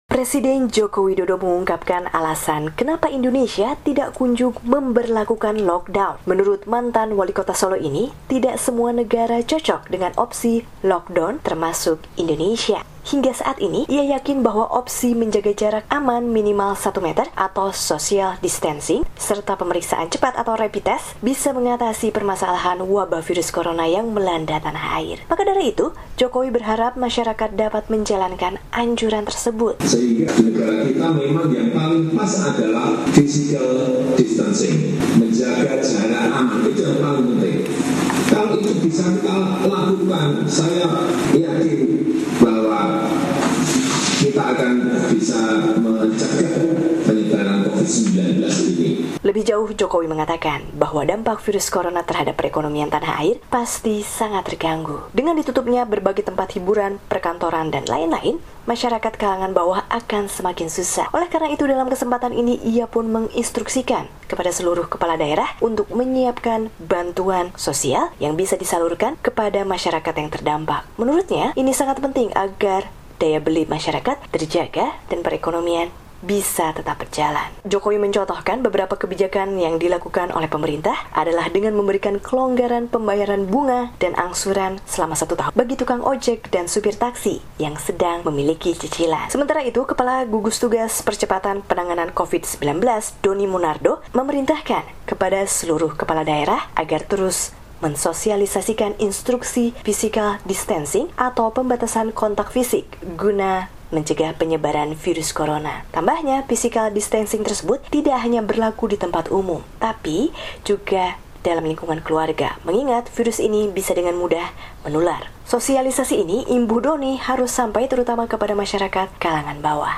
melaporkan dari Jakarta.